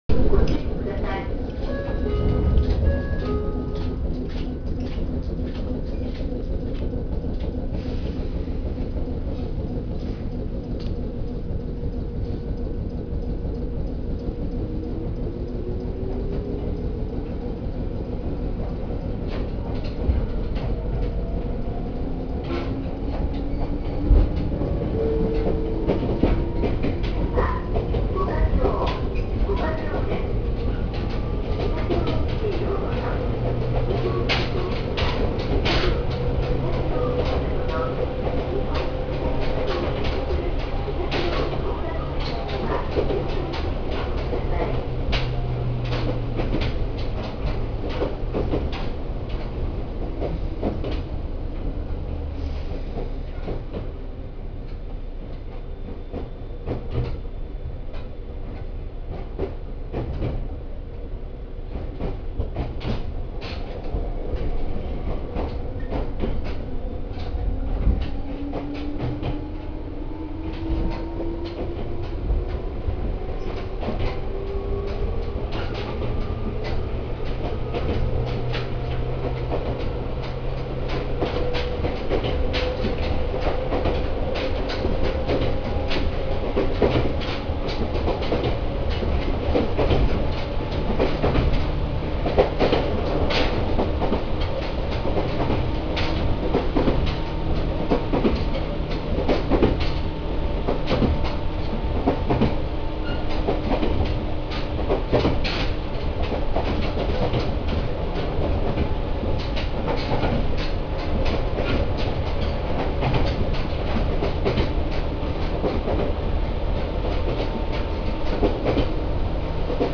【車両の音】愛知川〜五箇荘の走行音を追加。
・800系走行音
【本線】愛知川〜五箇荘（4分12秒：1.33MB）※ドアチャイム設置後
西武401系は一応抵抗制御の車両だったため、走行音に関してはごく普通です（同じ顔でツリカケだったのは451系）。扉の形態の違いこそあれど、どの編成も音は同じです。後にドアチャイムも設置されています。